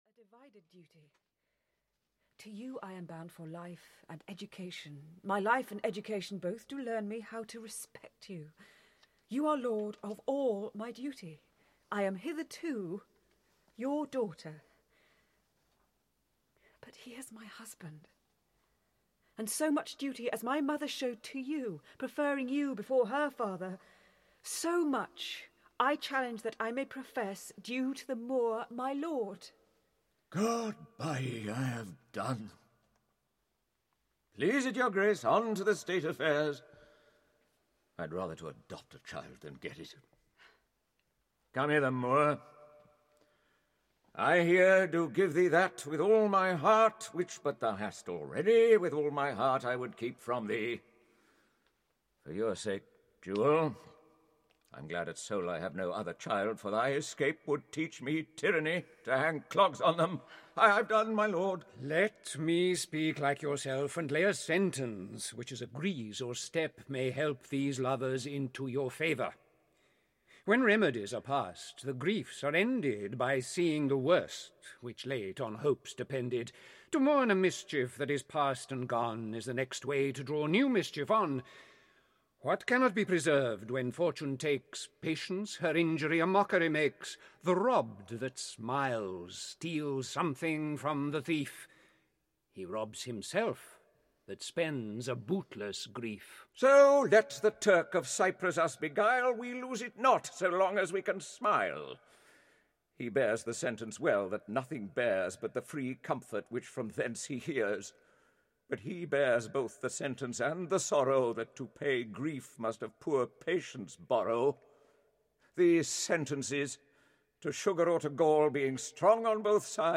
Othello (EN) audiokniha